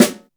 SC O-SNARE.wav